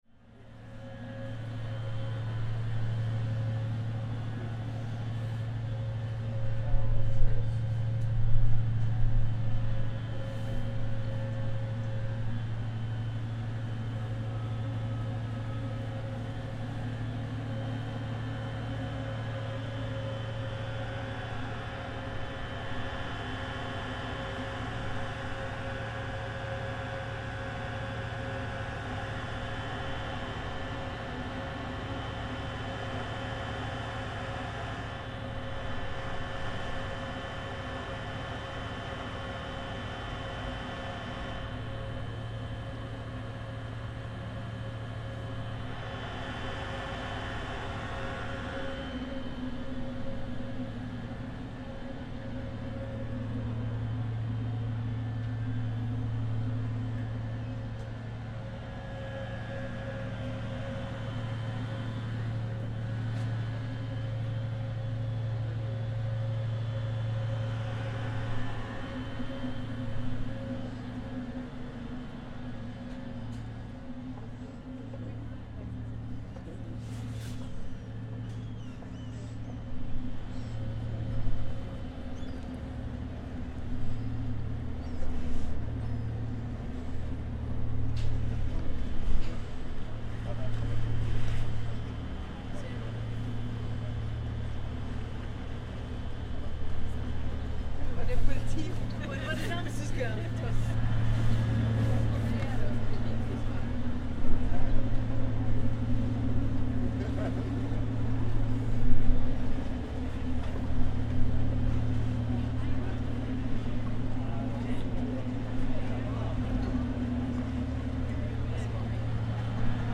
Docking in Helsinki harbour
The ferry from UNESCO World Heritage site Suomenlinna runs frequently throughout the day from Helsinki harbour - the short 20 minute trip contains mostly tourists, with some residents making the back and forth (850 people live on the Suomenlinna islands).